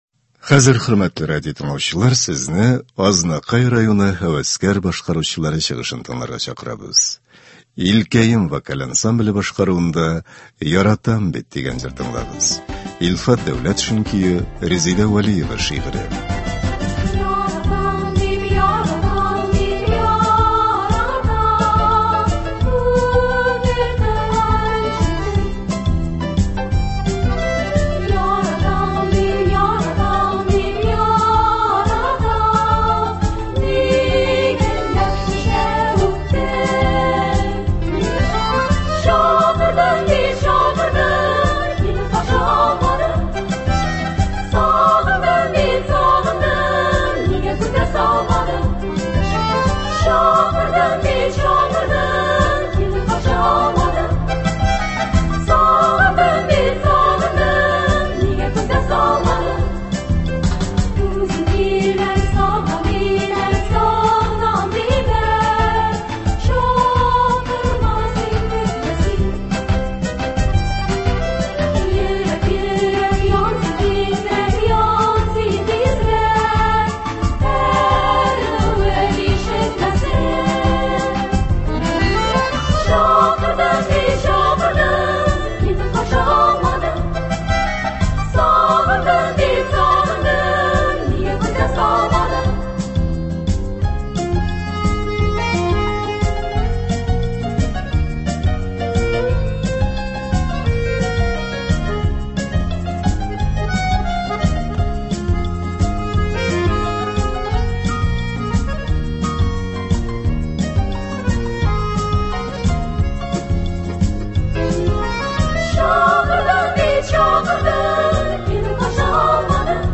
Концерт (17.04.23)